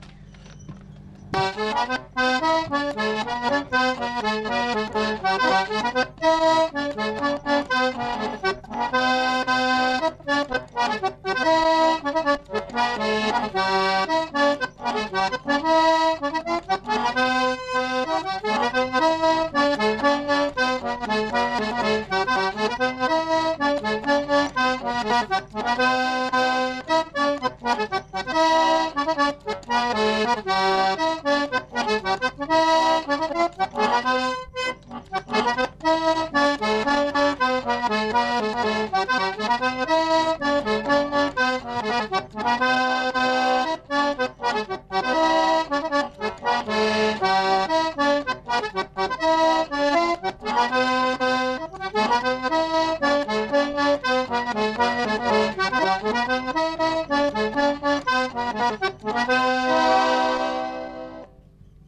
Quadrille (3e f.)
[Gers. Groupe de musiciens. Cuivres]